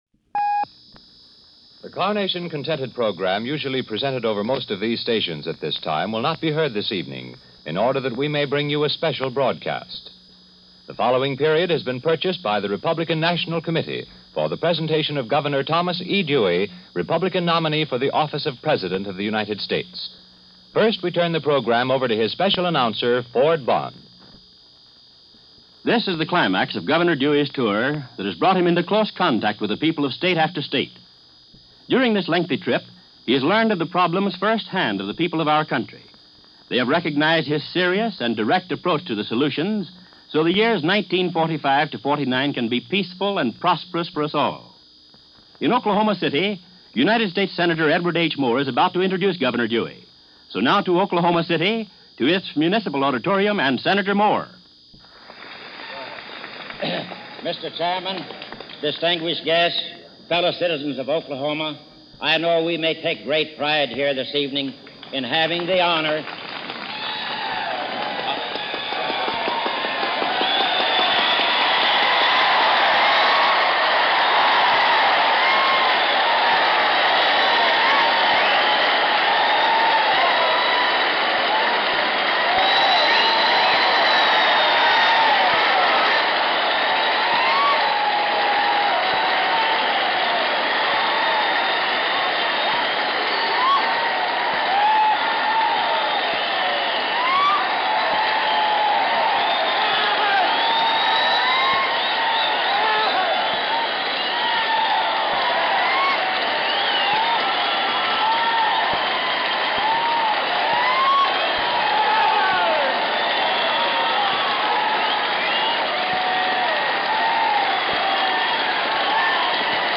Thomas E. Dewey – Campaign address – September 25, 1944